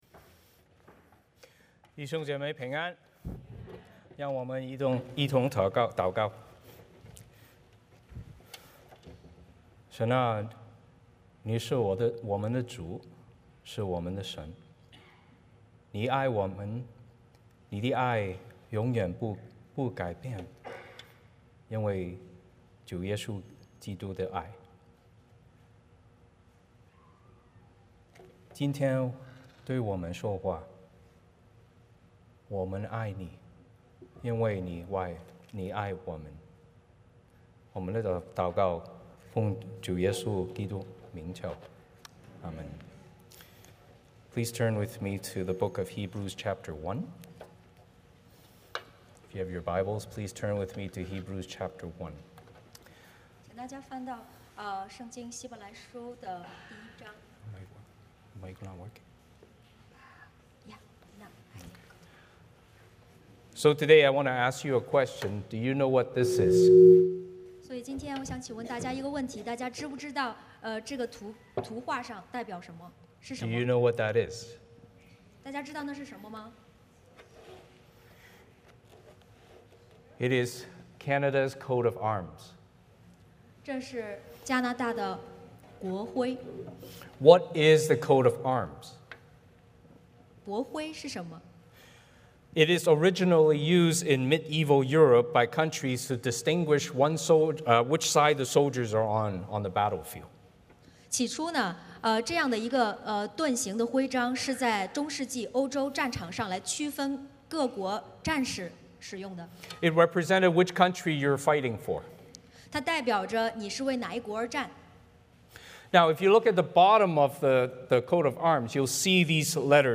Service Type: 主日崇拜 欢迎大家加入我们的敬拜。